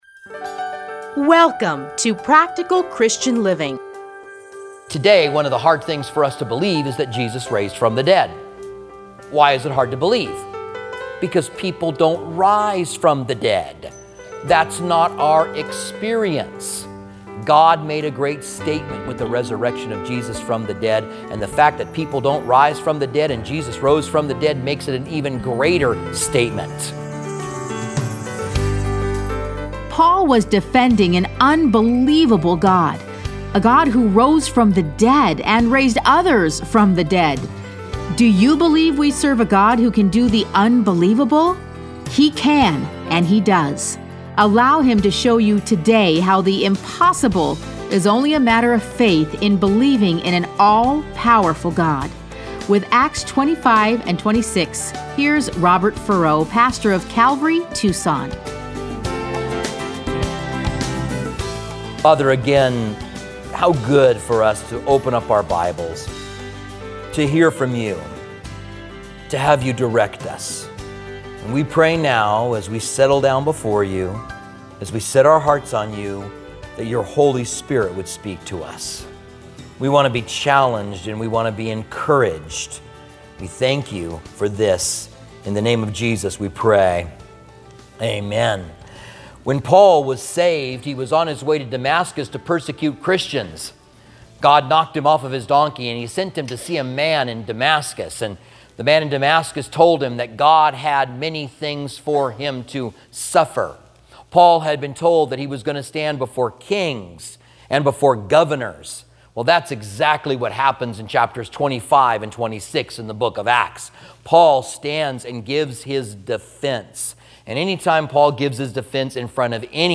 Listen to a teaching from Acts 25-26.